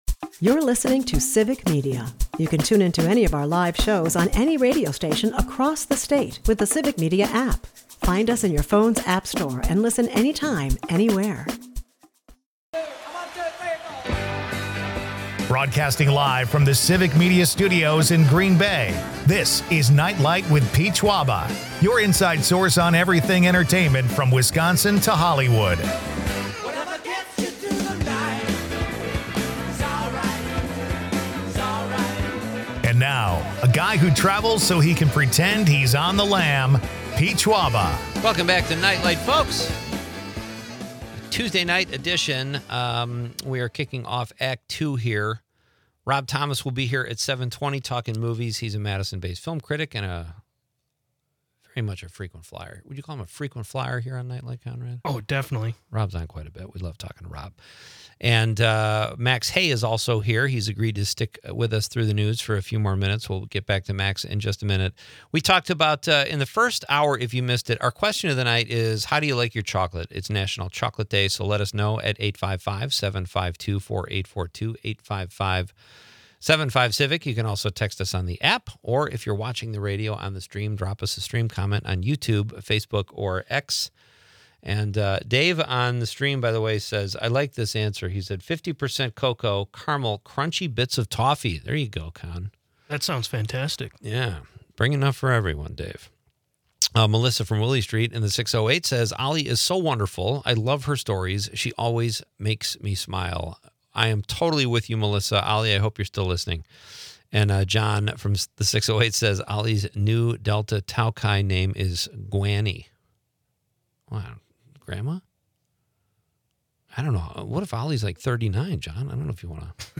Meanwhile, listeners share their chocolate preferences in honor of National Chocolate Day, ranging from Reese's cups to chocolate martinis. It's a mix of hearty laughs, cinematic insights, and chocolate cravings!